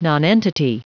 Prononciation du mot : nonentity